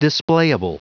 Prononciation du mot displayable en anglais (fichier audio)
Prononciation du mot : displayable